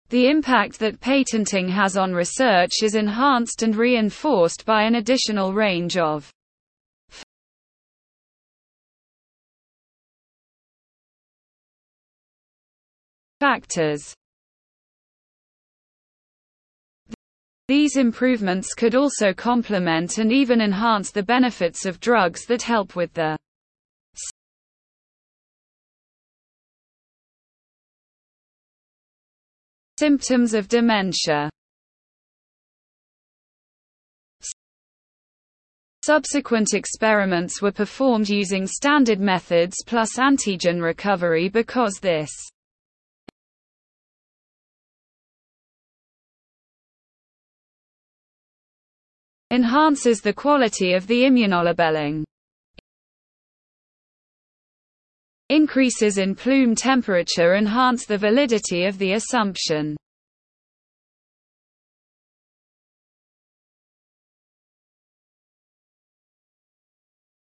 Remove glitch between silence
I’ve made some TTS files for the purpose of language learning and used Extend Silence plug-in. After that there are small glitches that I pointed on screenshot.